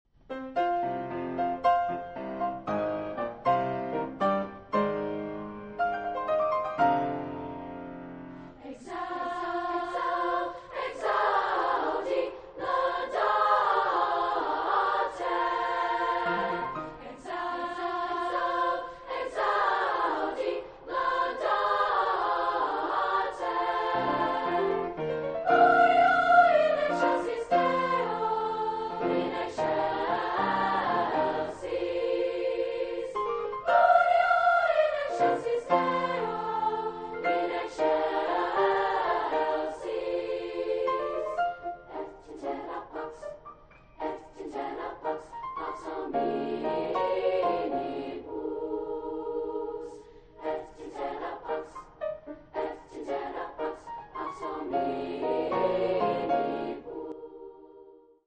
Williamsburg, Virginia, March 10th, 2001